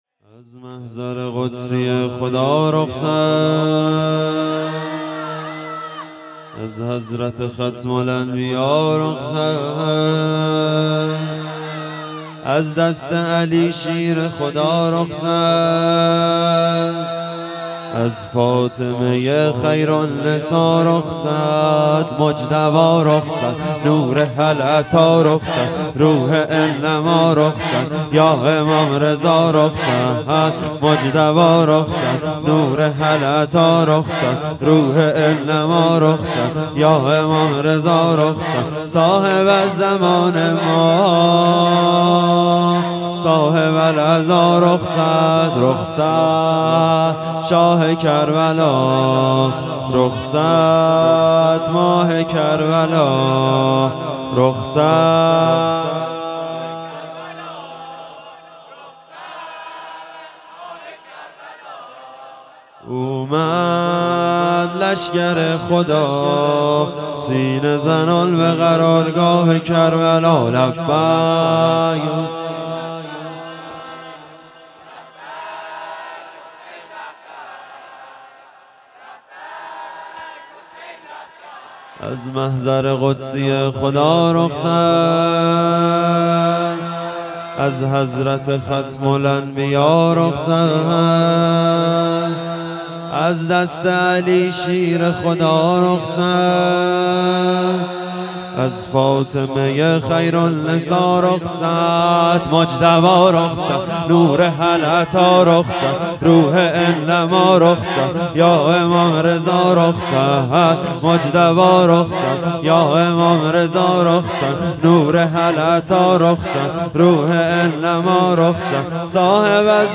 شب دهم محرم ۱۴۴۴